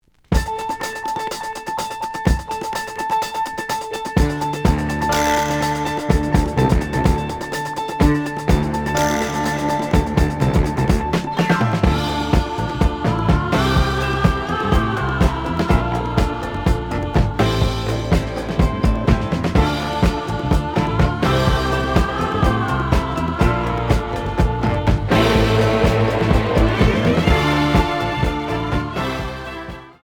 (Mono)
試聴は実際のレコードから録音しています。
●Format: 7 inch
●Genre: Disco